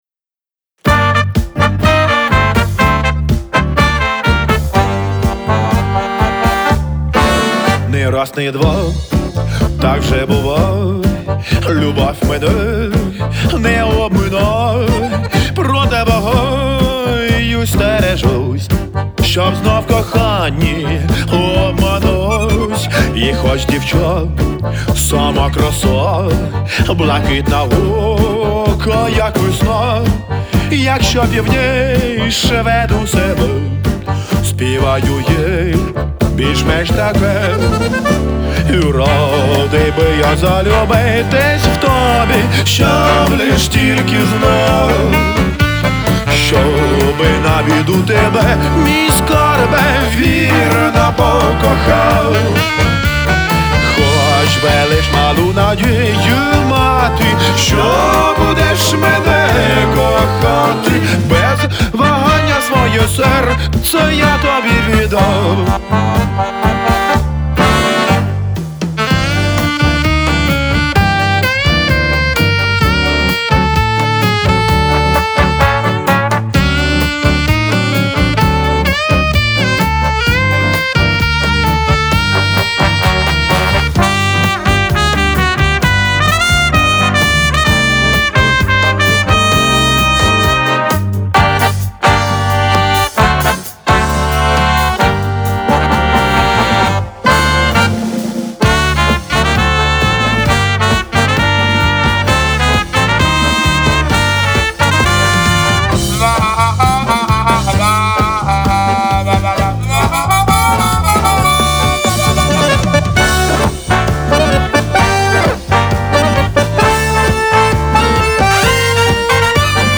Стиль : retro pop